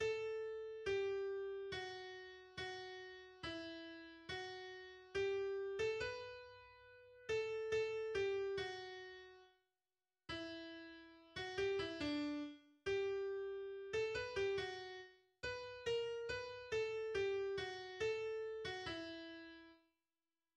en la majeur
Genre Concerto pour piano